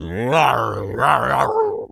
tas_devil_cartoon_06.wav